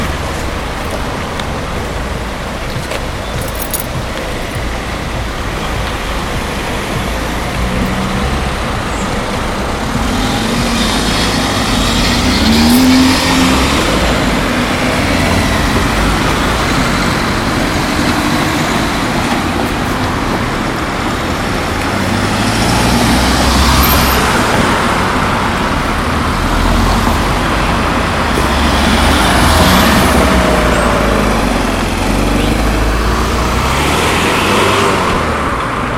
Questo suono ha una locazione un po’ particolare, noi ti consigliamo di ascoltare all’incrocio di via Filzi con via della Costituzione, ma in realtà si trova in quasi tutta la città!
4 anni Indizi dei bambini È un rumore “ROMBOSO” e anche rumoroso! Noi lo abbiamo registrato di mattina sul ponte, però lo puoi trovare da tutte le parti, perché le persone vanno sempre da qualche parte!